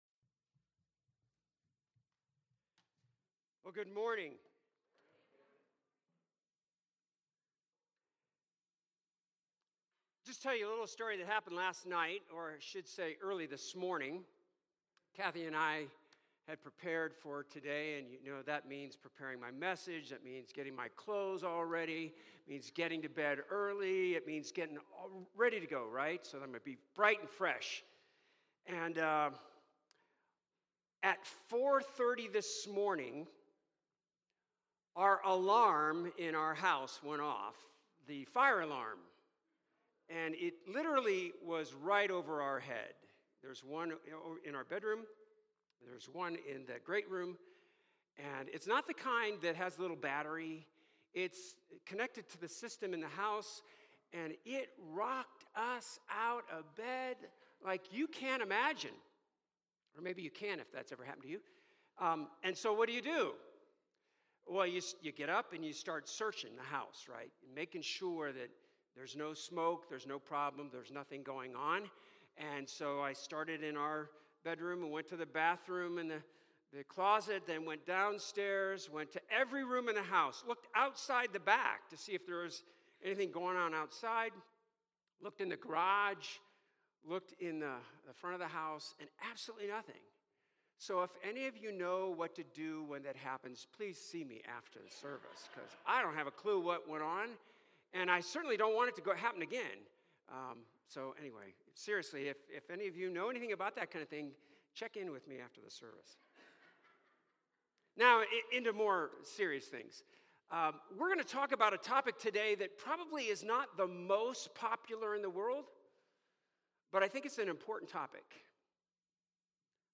Visiting Pastor